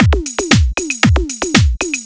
116 BPM Beat Loops Download